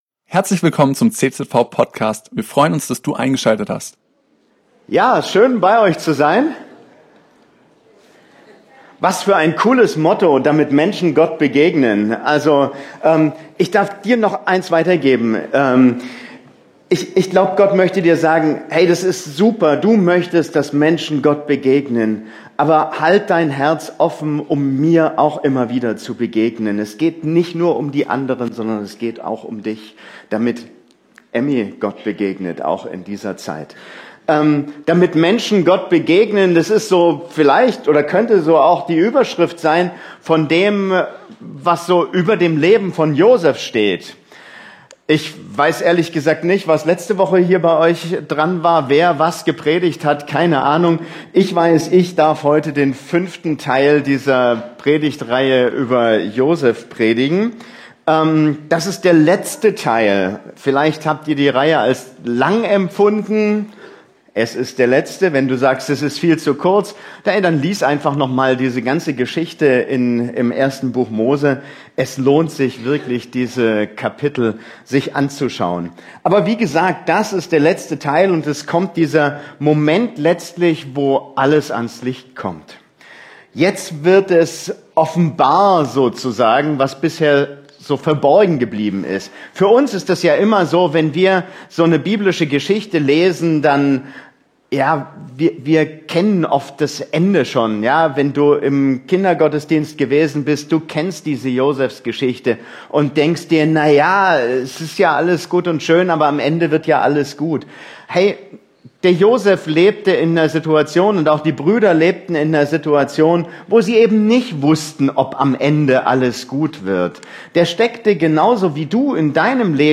Abschlusspredigt